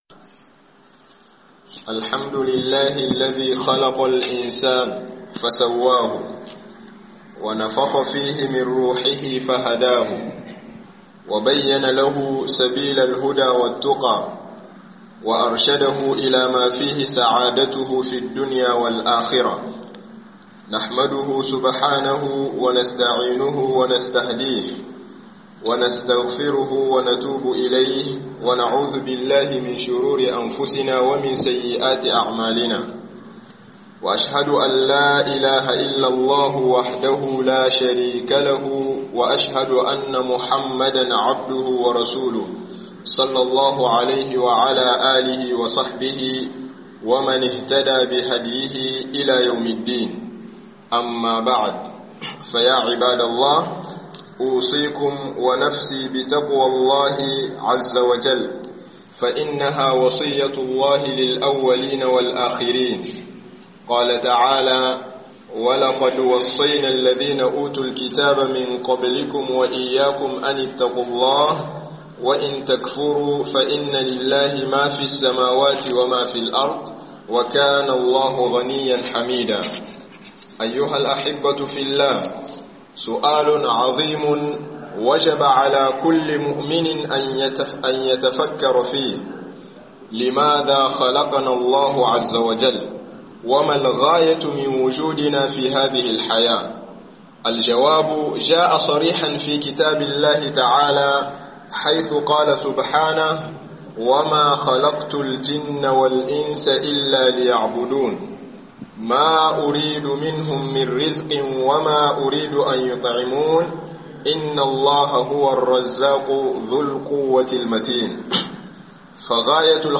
Dalilin Halittar ɗan Adam - Hudubobi